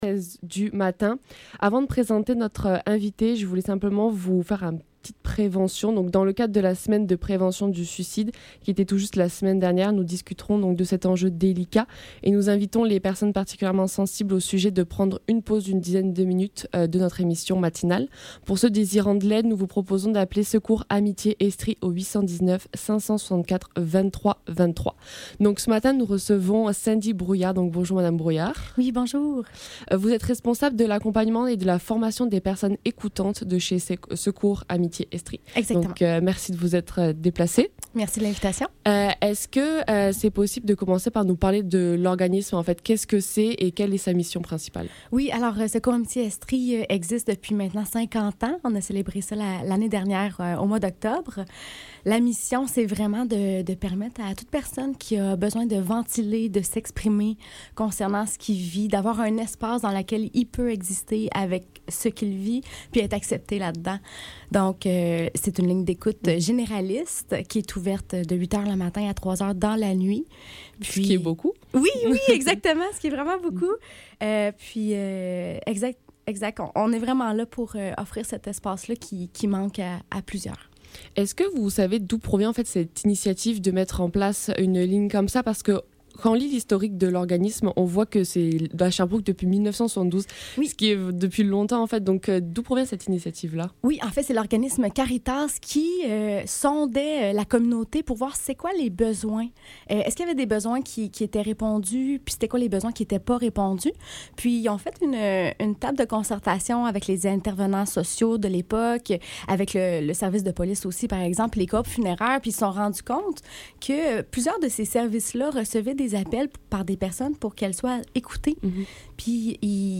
Le SEPT - Entrevue